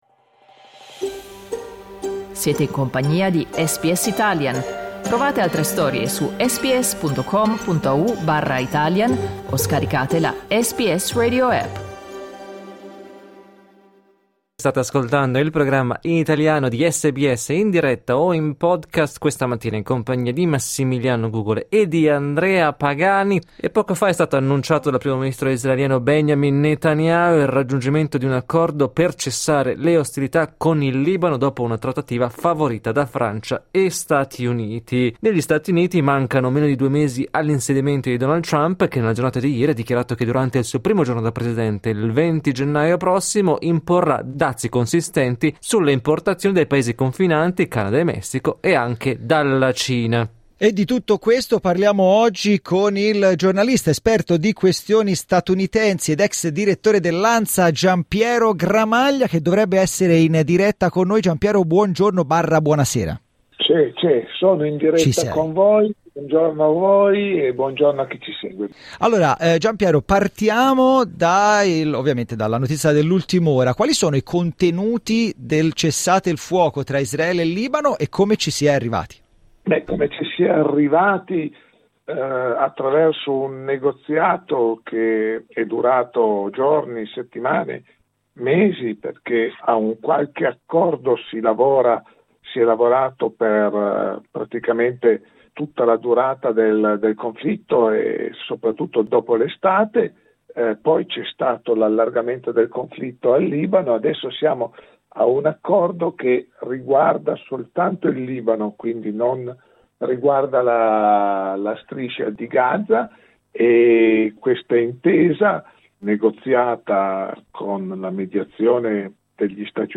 Ascolta l'analisi del giornalista